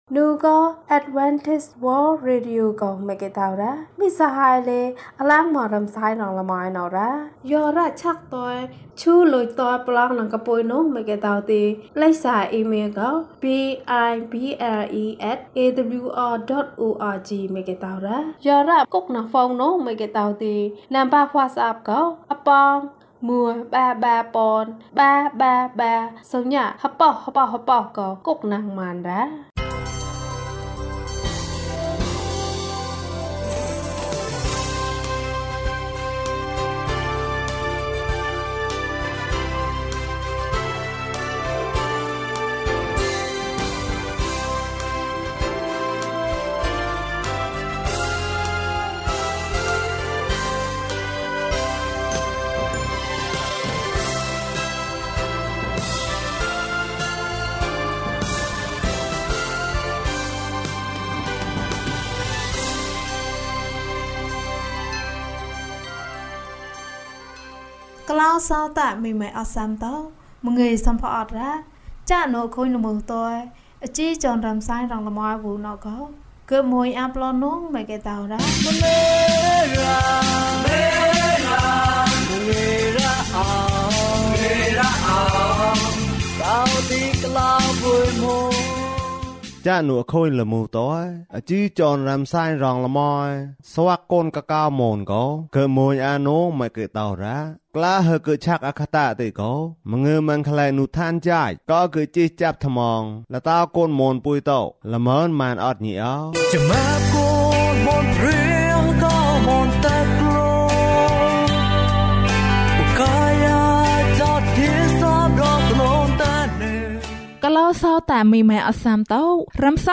ယေရှုက သင့်ကို ချစ်တယ်။ ကျန်းမာခြင်းအကြောင်းအရာ။ ဓမ္မသီချင်း။ တရားဒေသနာ။